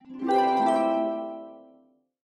Откройте для себя коллекцию звуков нового уровня — инновационные аудиоэффекты, футуристические мелодии и необычные композиции.
Звук разблокировки второго уровня для разработчика игр